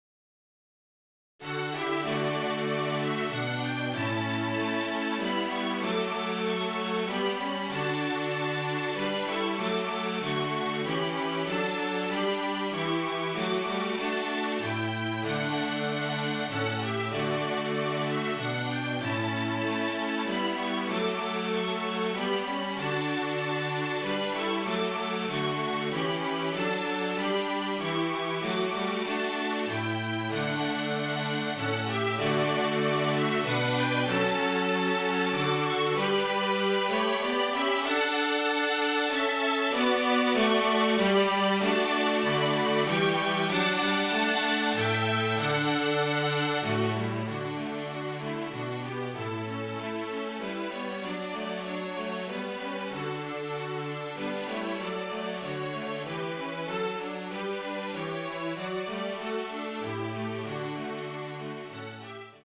Flute, Violin and Cello (or Two Violins and Cello)
MIDI
(Flute Trio version)